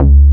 BAS_Jupiter 8 10.wav